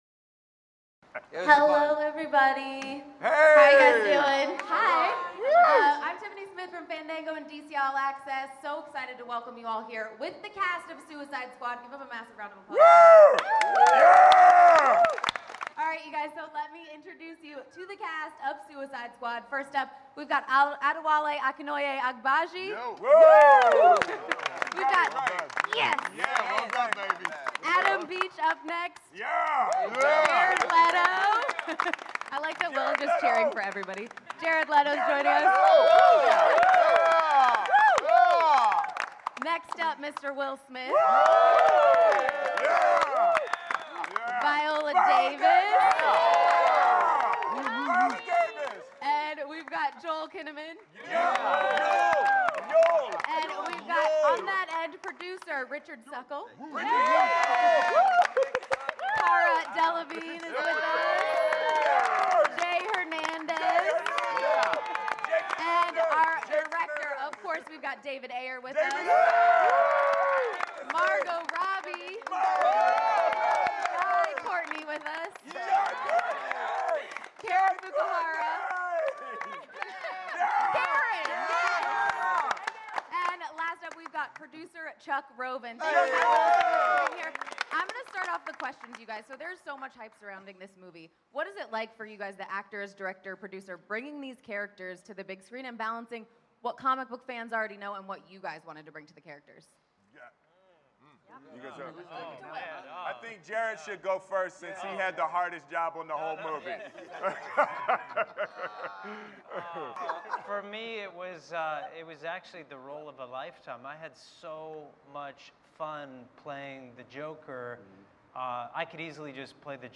SUICIDE SQUAD – Press Conference Audio